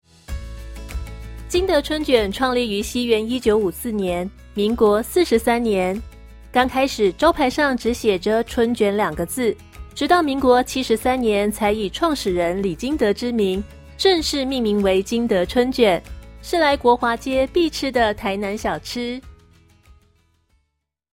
中文語音解說